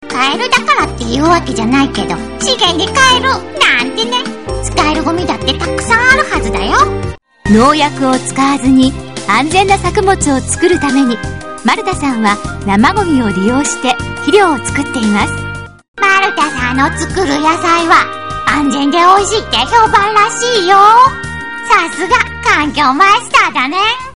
ナレーター
ボイスサンプルがある番組などをいくつかチョイスしました。